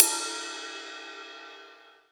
Percs
DJP_PERC_ (15).wav